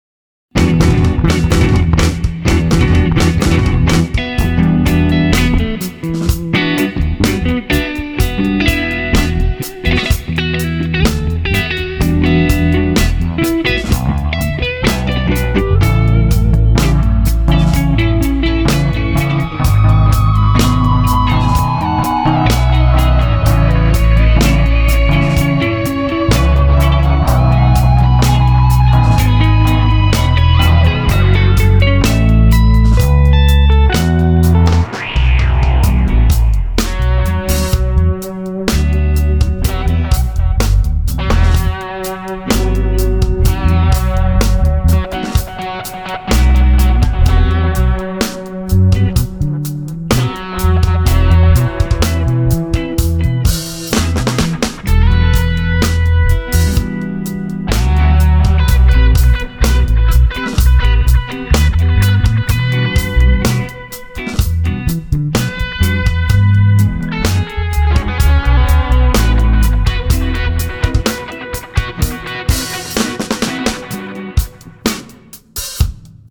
незаконченная пока композиция:
конечно в разы лучше всего, что здесь выкладывают, но тем не менее все равно гитаризма слишком много в песне...
очень пrиятно звучит... тrебую веrсию целиком!!!
почти тоже самое, что и в файле выше, только огг, чуть получше микс и добавлена небольшая гитарная часть нарочито простая в конце